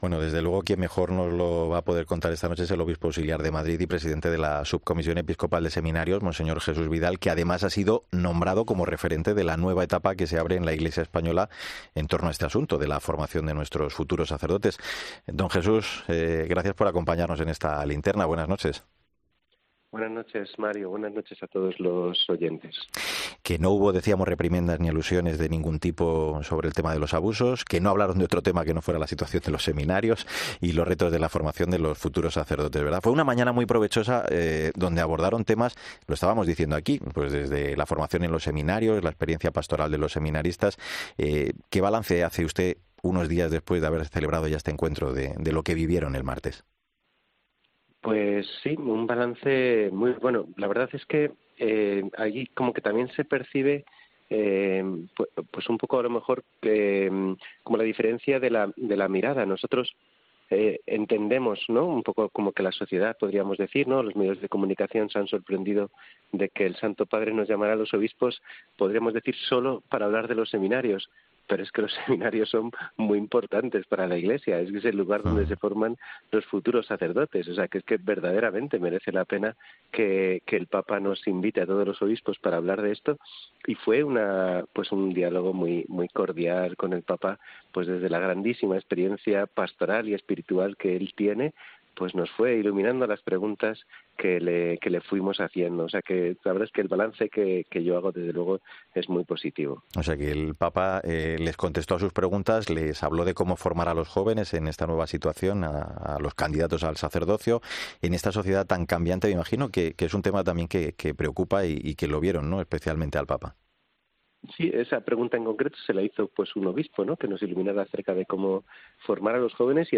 El presidente de la Subcomisión Episcopal para los Seminarios reflexiona en COPE sobre la formación de los futuros sacerdotes en los seminarios